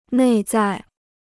内在 (nèi zài): inner; internal.